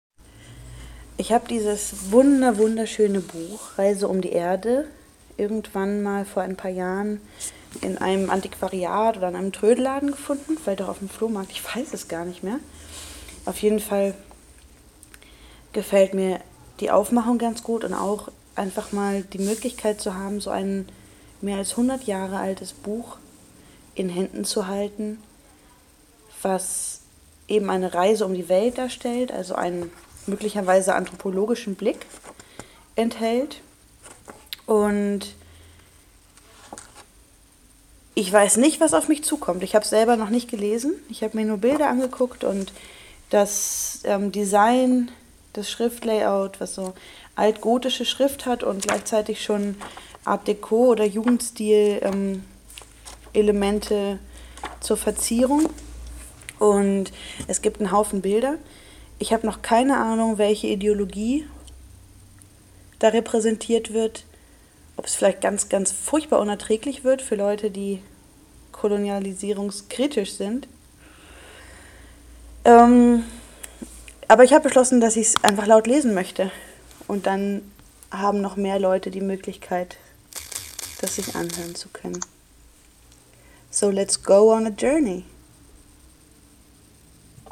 Ich werde aus dem Buch vorlesen, was nicht so einfach ist, da es in alt-gotischer Schrift verfasst ist.